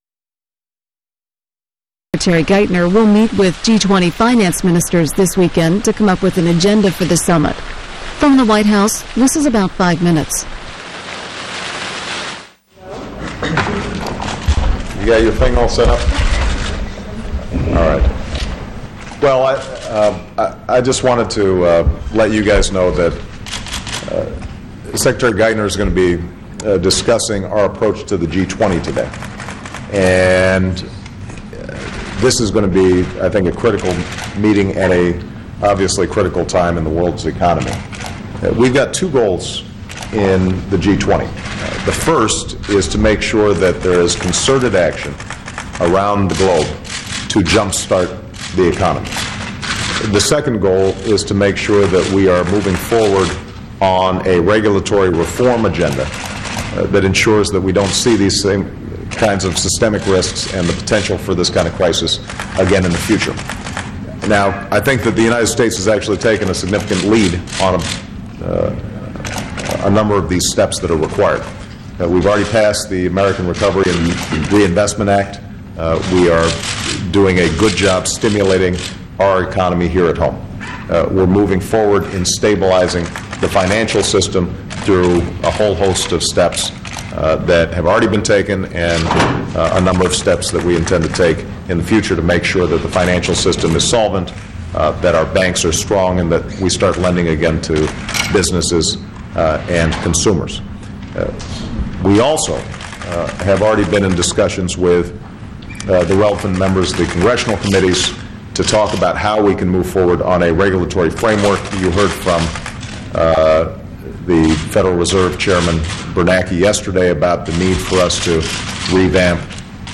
U.S. President Obama speaks to the press after receiving his daily economic briefing from Treasury Secretary Geithner